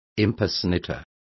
Complete with pronunciation of the translation of impersonator.